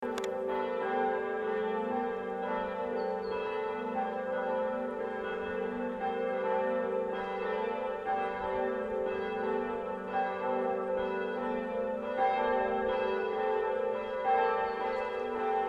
Glocken der ev. Erlöserkirche, Annen
Das neue Bronzegeläut wurde 1996 bei Petit & Gebr. Edelbrock in Gescher gegossen.
Zu der Glocke vom Bochumer Verein, Ton h° mit einem Durchmesser von 1794 mm und einem Gewicht von 2500 kg sind im Turm:
Die zweite Glocke, Ton g¹ mit einem Gewicht von 770 kg und einem Durchmesser von 1035 mm,
Die dritte Glocke, Ton a¹, mit einem Gewicht 590 kg und einem Durchmesser von 960 mm, hat die Inschrift:
Die vierte Glocke, Ton h¹ mit einem Gewicht von 460 kg und einem Durchmesser von 866 mm, hat die Inschrift:
erloeserkirche_Glocken.MP3